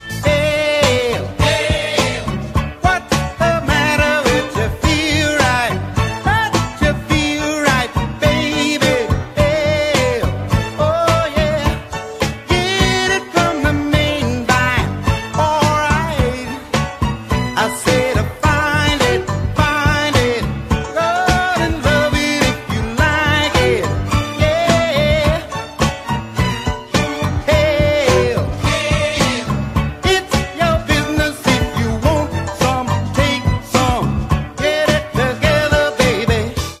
Catégorie POP